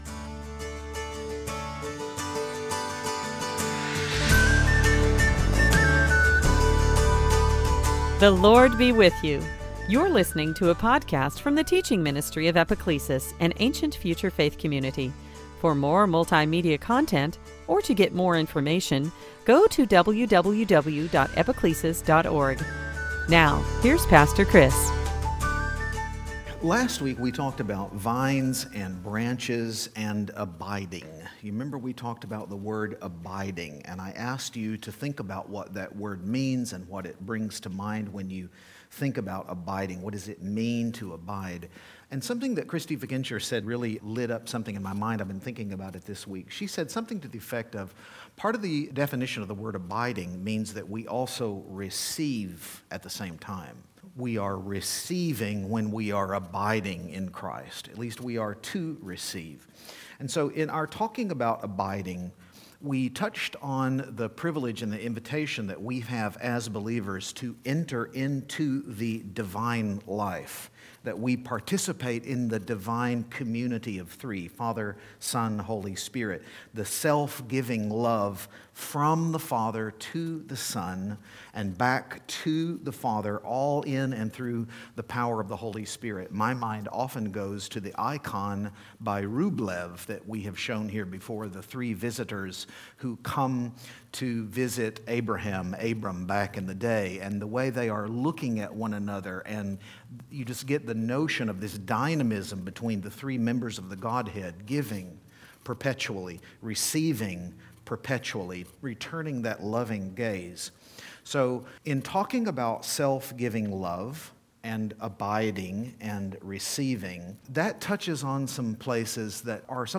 2021 Sunday Teaching friends love servants slaves Eastertide